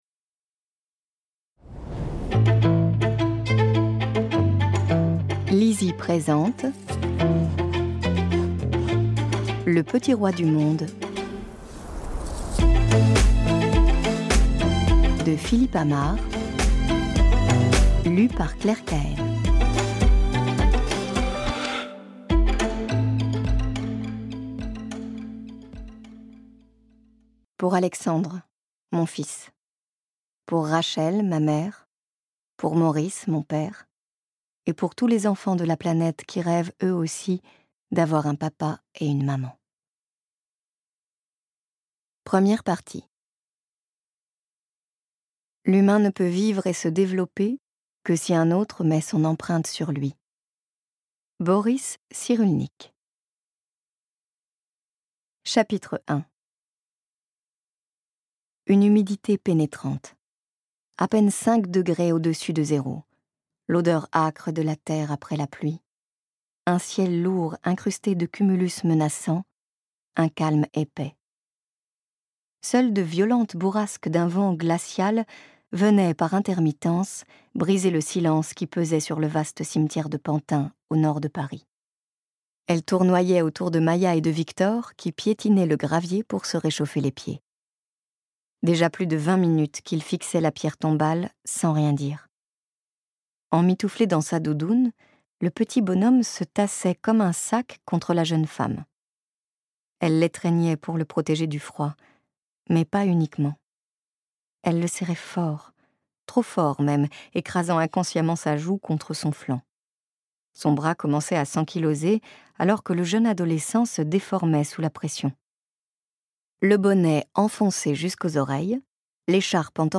je découvre un extrait - Le petit roi du monde de Philippe Amar